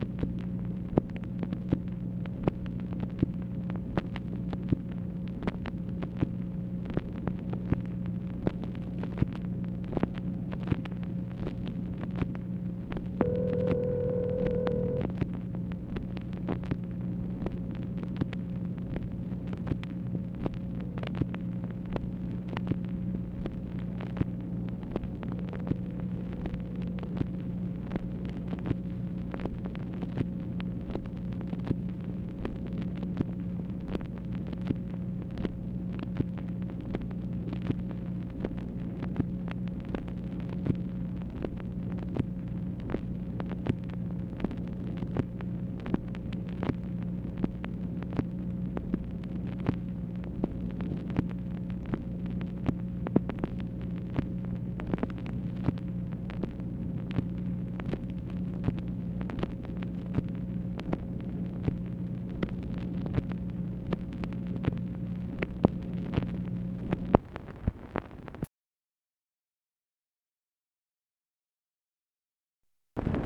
MACHINE NOISE, September 26, 1968
Secret White House Tapes | Lyndon B. Johnson Presidency